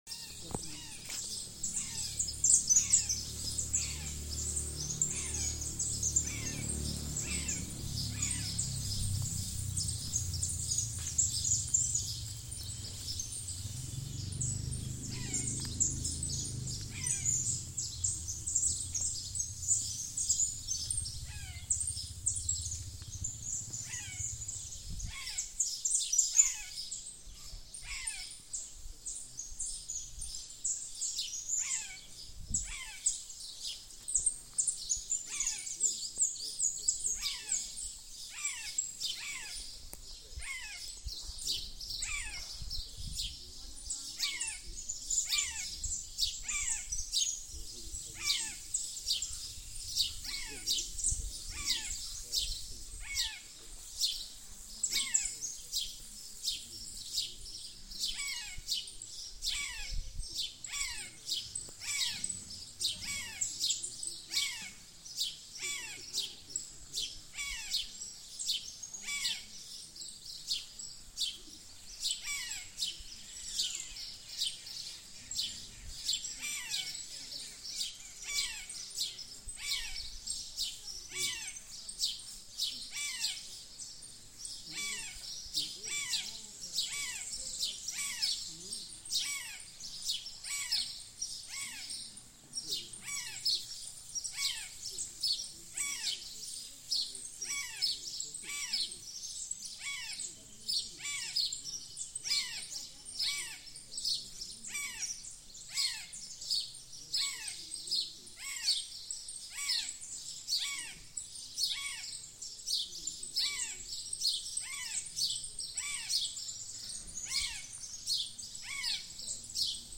Chimango Caracara (Daptrius chimango)
Detailed location: Colalao Del Valle - Ruta 40
Condition: Wild
Certainty: Observed, Recorded vocal
Chimango.mp3